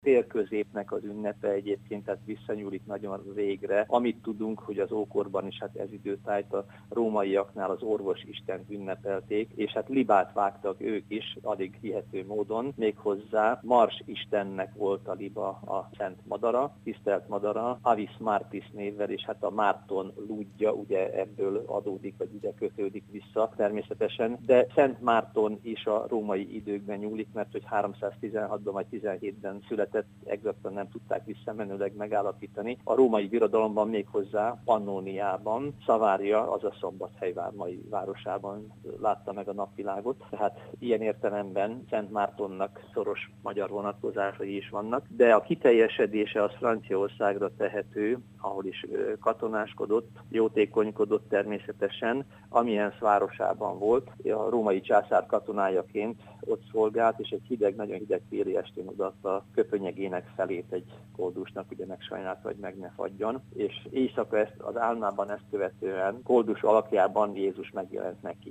Zsírosné Pallaga Mária polgármester egy korábbi alkalommal beszélt arról, milyen gépparkot tudnak kialakítani a támogatásból.